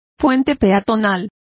Complete with pronunciation of the translation of footbridges.